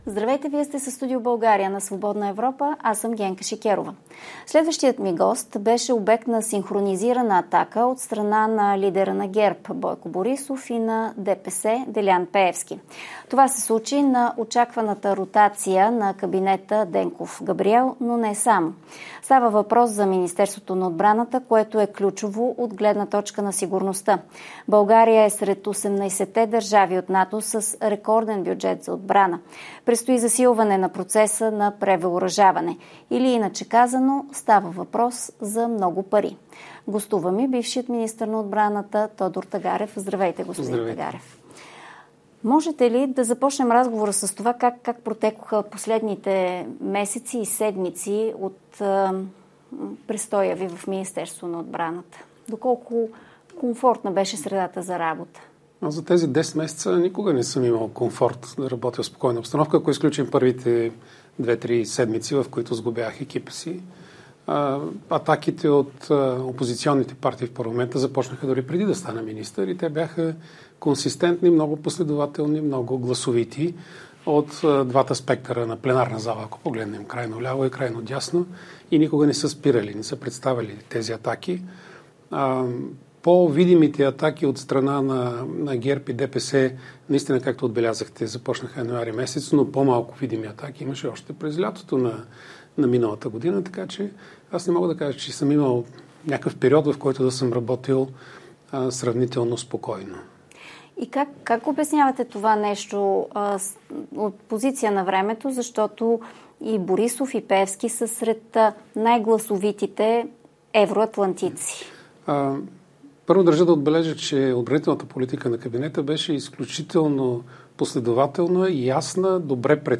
И каква е ситуацията на фронта в Украйна в момента? Тодор Тагарев е в Студио България с Генка Шикерова.